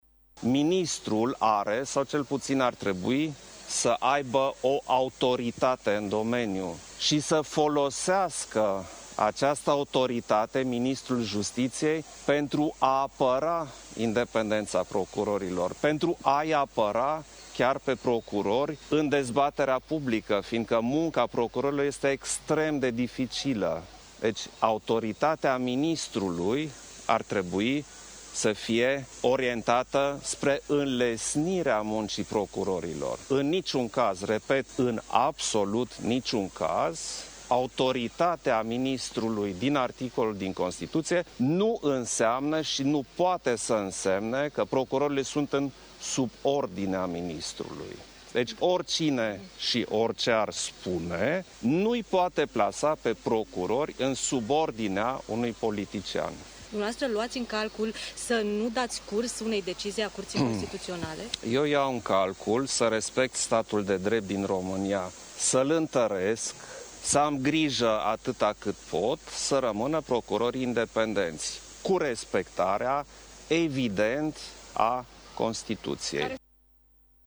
Acesta este punctul de vedere exprimat de președintele Klaus Iohannis, în urmă cu puțin timp, la Palatul Cotroceni.